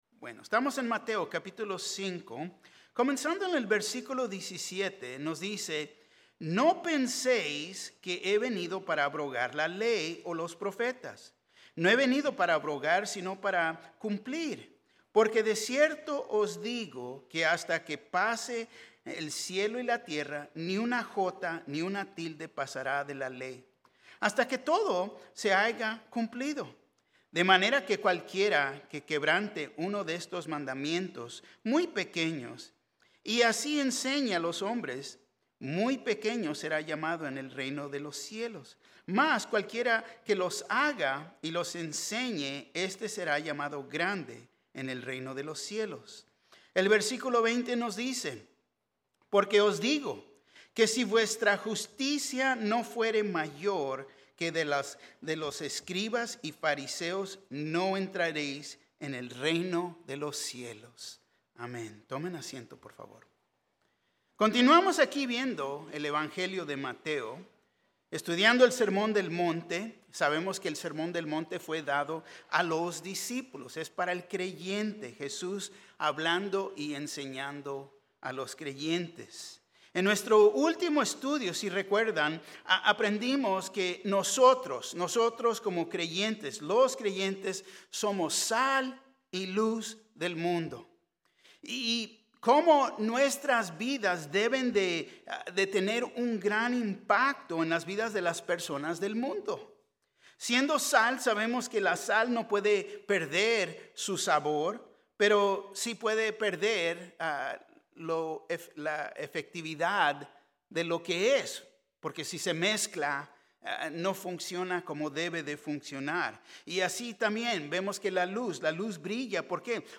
Mensaje
Un mensaje de la serie "Estudios Tématicos." Las oraciones que hacemos ¿para quién son?